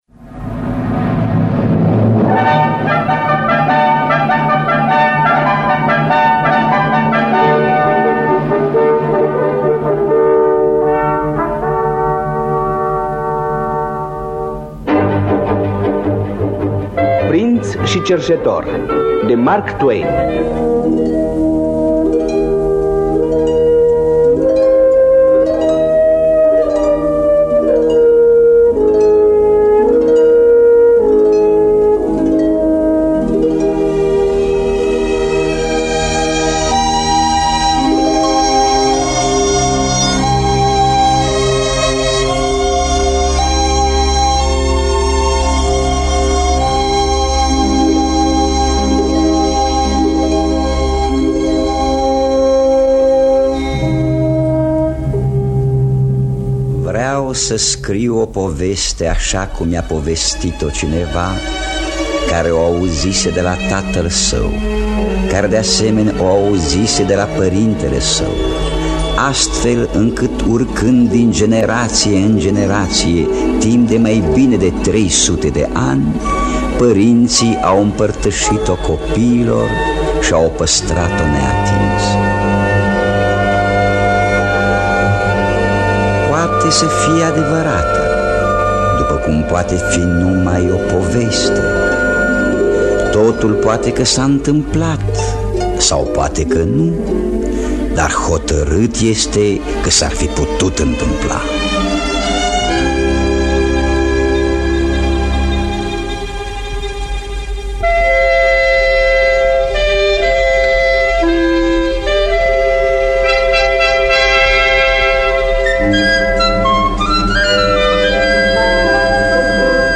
Dramatizarea radiofonică de Serghei Mihalkov.